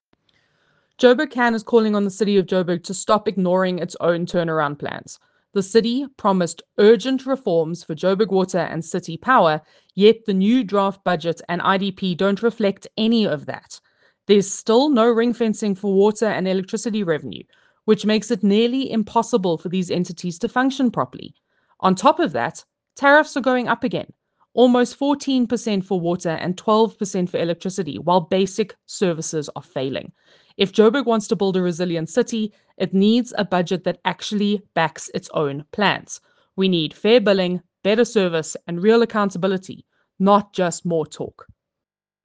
A voicenote with comment